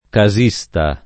casista [ ka @&S ta ]